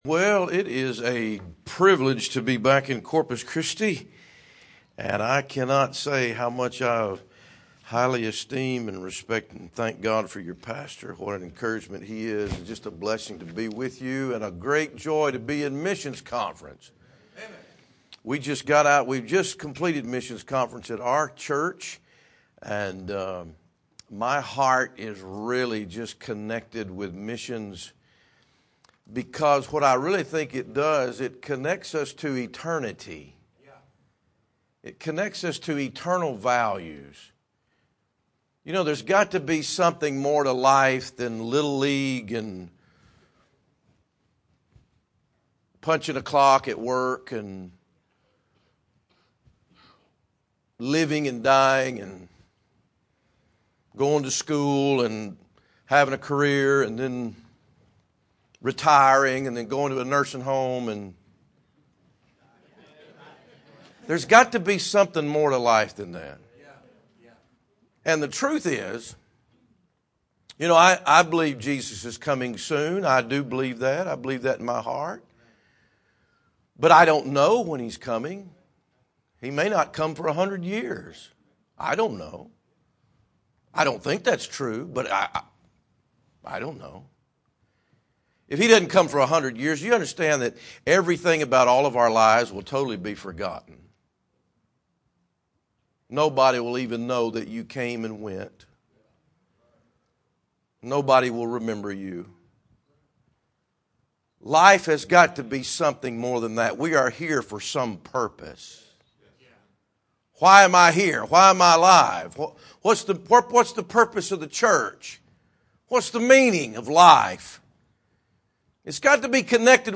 Sermons
missions conference